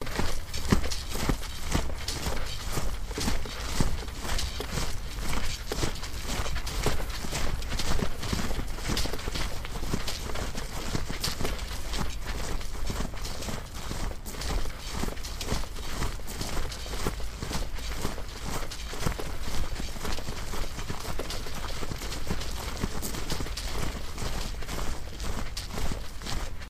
Medieval Combat » WeaponSwipe01
描述：Simple technique of swiping a cheese grater past mic (SM57).
标签： adpp medieval war knights battle swoosh sword fantasy fight fighting swords swipe weapon knight soldier clash combat blade warrior
声道立体声